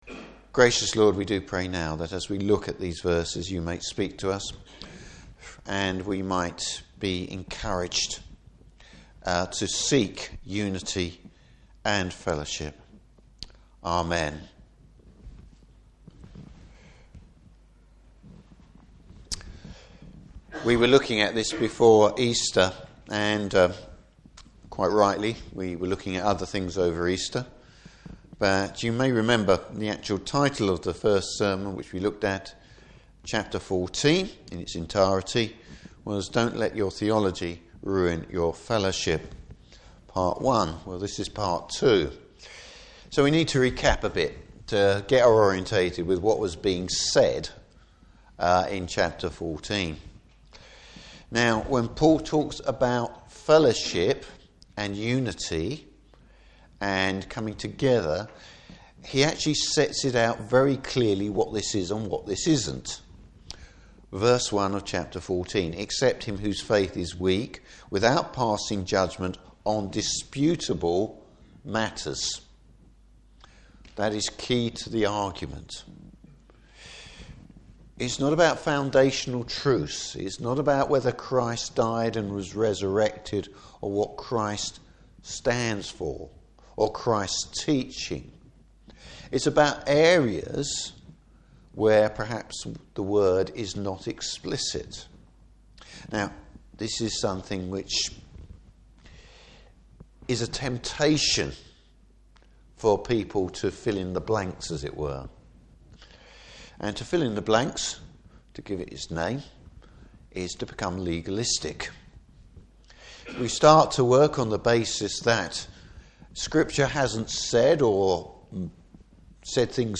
Service Type: Morning Service Don’t let your fellowship with other Christians be based only on head knowledge.